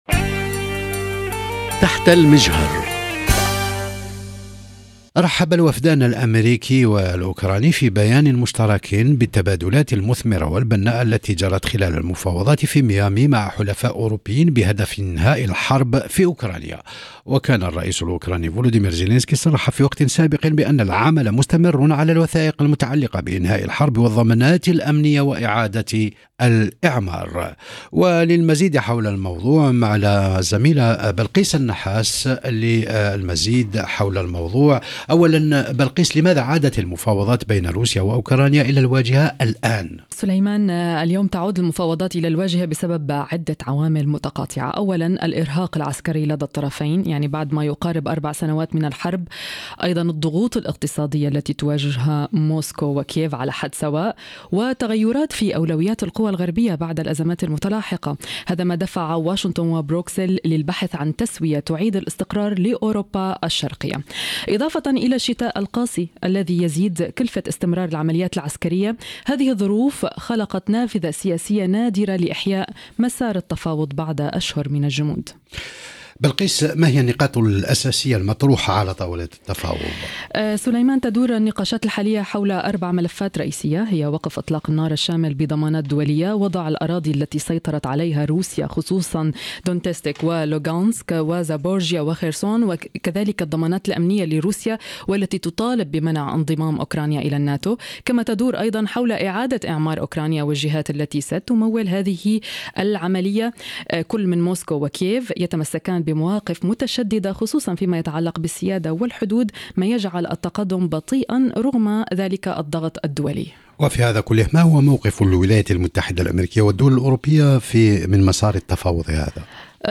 لمناقشة تطوّرات هذا الملف وتعقيداته، حوار بين الزميلين